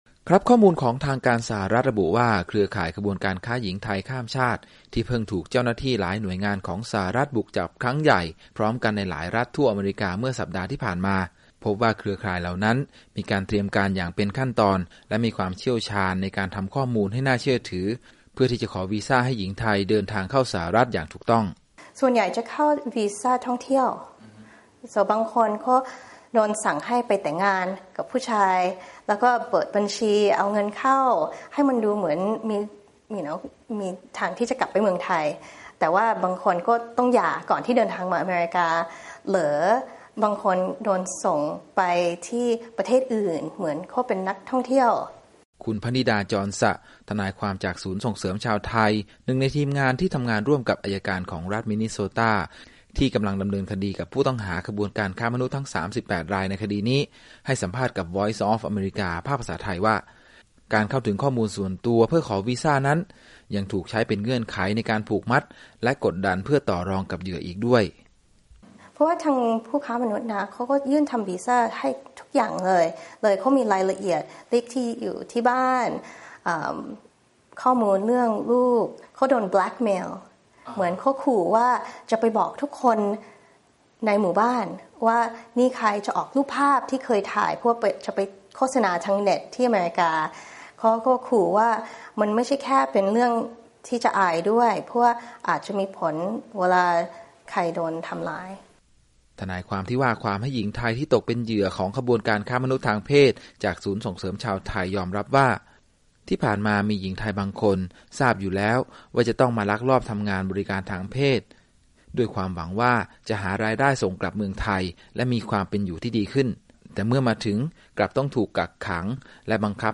Breaking News